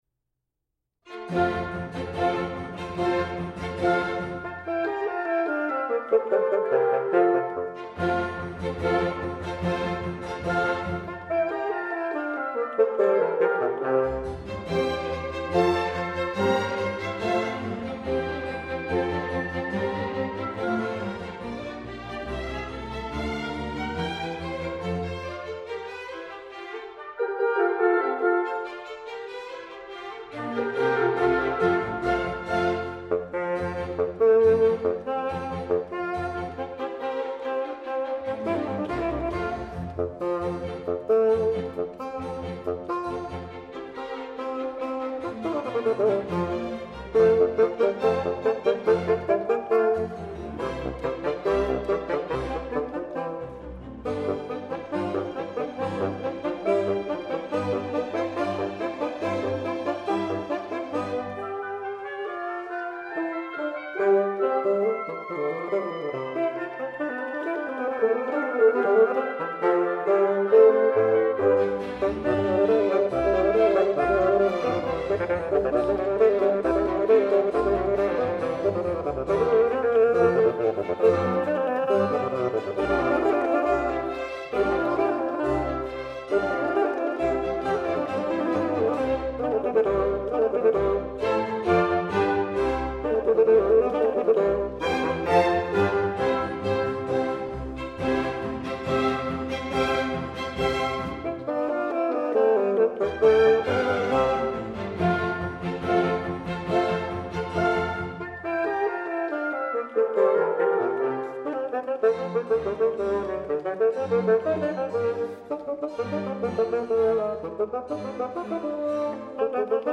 finale allegro
os fagotistas usam fagotes no sistema francês